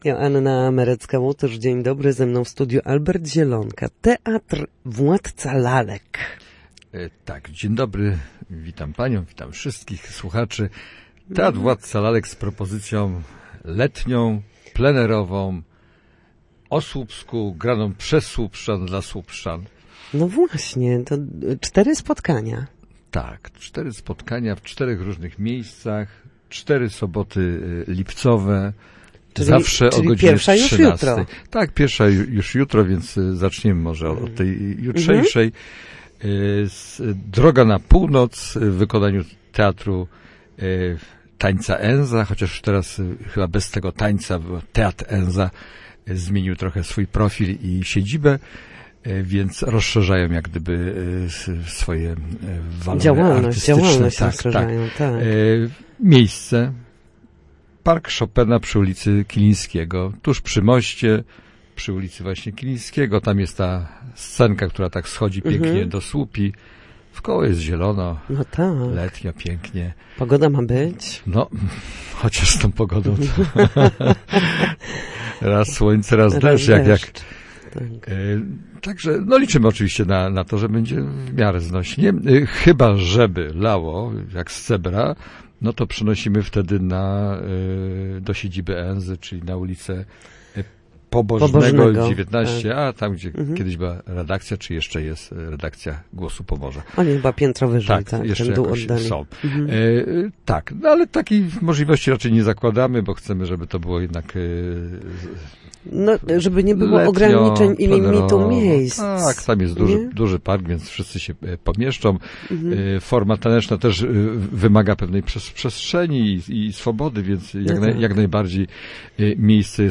Gościem Studia Słupsk